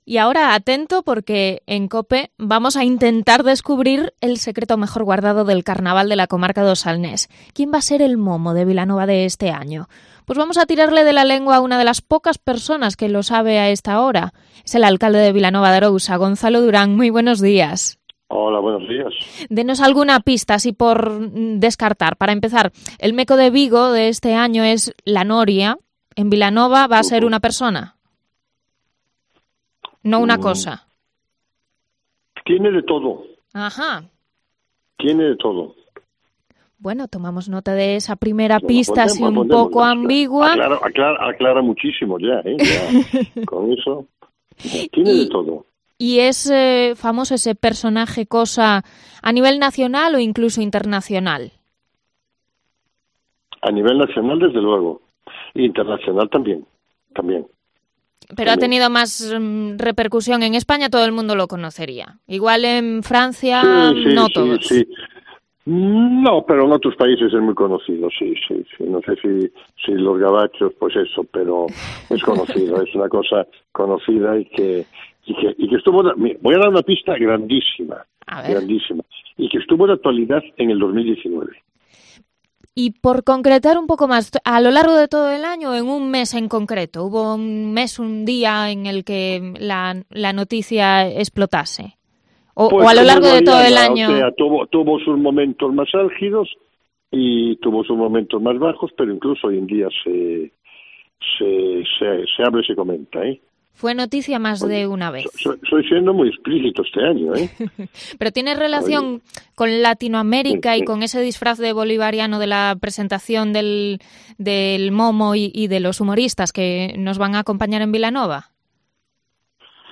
Entrevista al alcalde de Vilanova previa al Momo 2020